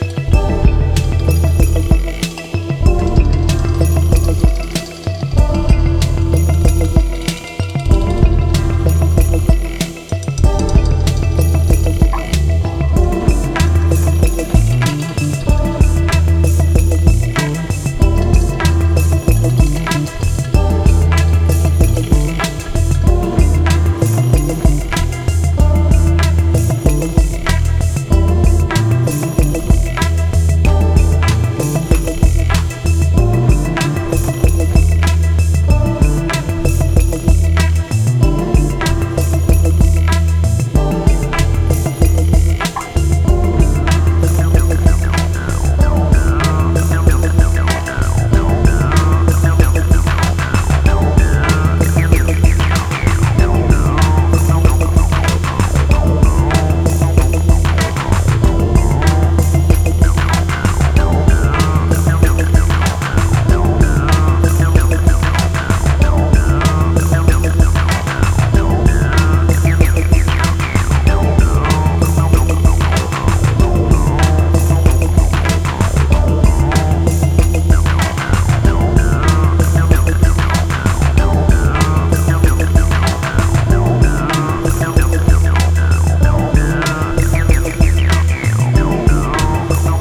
ギター/ベースの生演奏にアシッドラインを加えてミニマルに展開されるクラウトロック調のディープなダンスナンバー